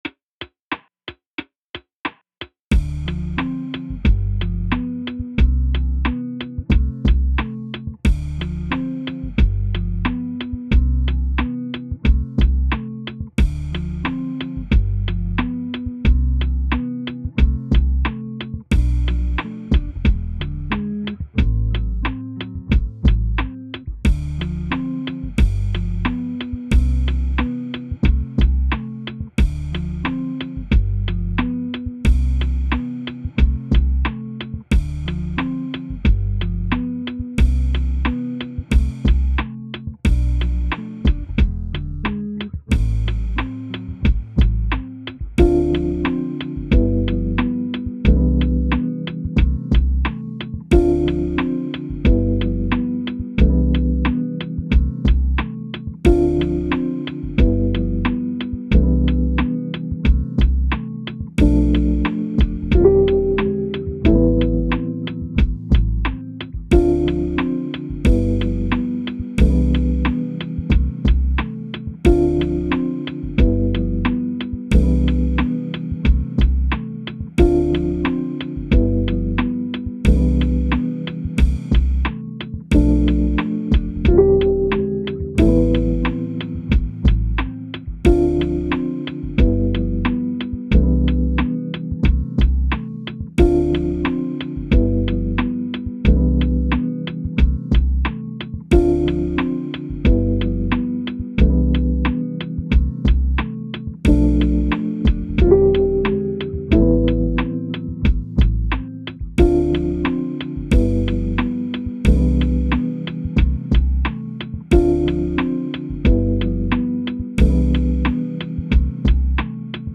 Chords are: Dm G7 Cmaj7 x3 Bm E7 Am